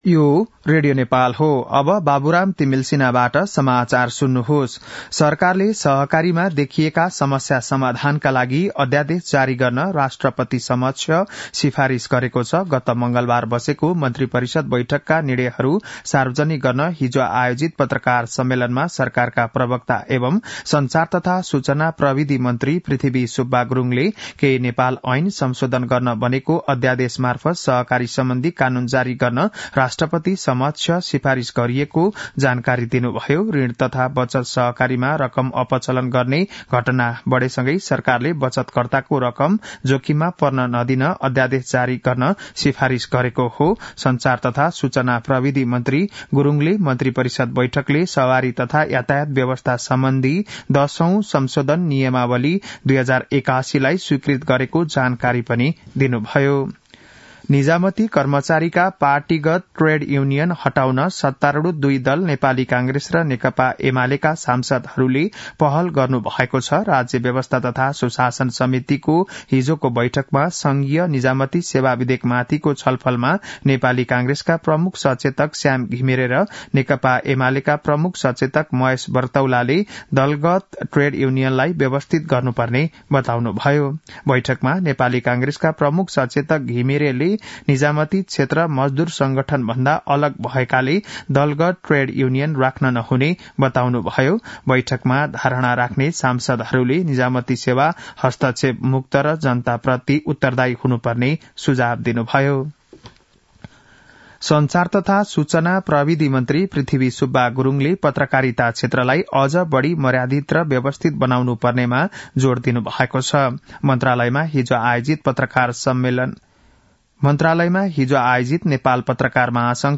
बिहान ११ बजेको नेपाली समाचार : १३ पुष , २०८१
11-am-nepali-news-1-21.mp3